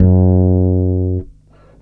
20-F#2.wav